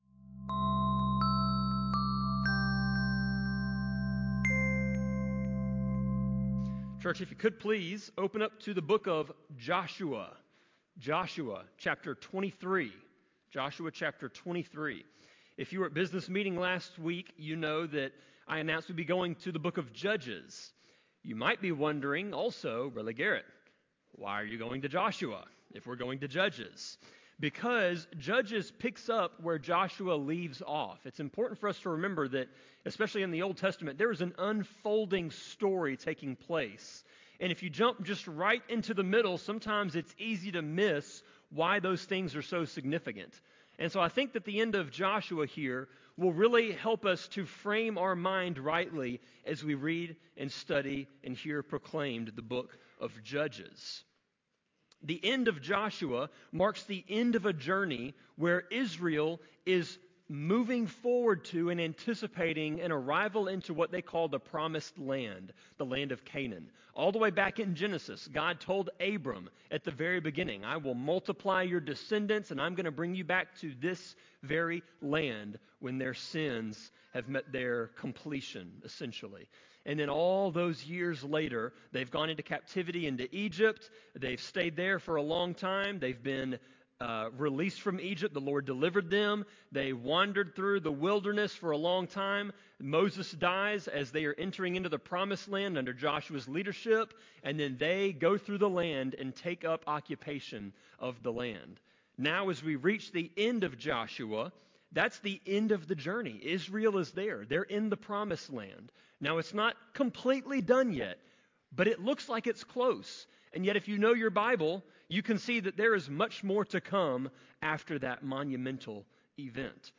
Sermon-25.8.24-CD.mp3